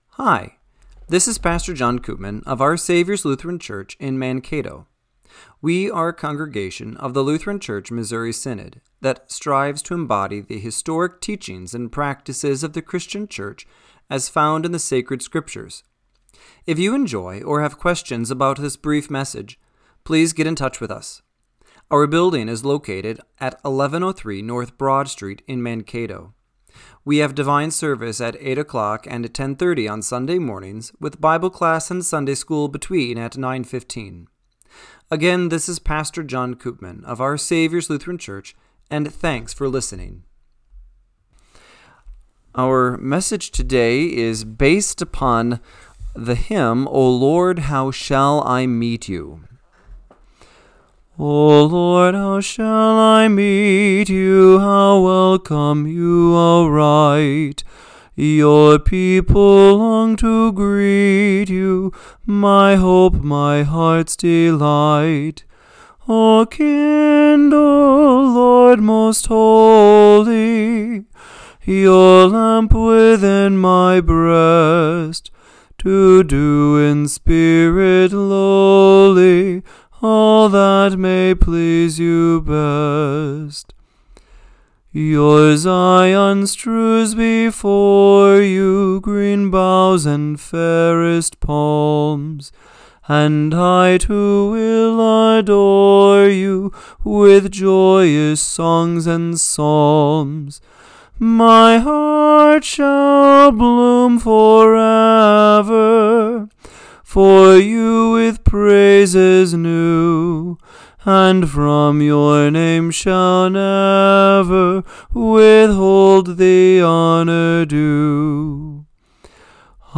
Radio-Matins-12-14-25.mp3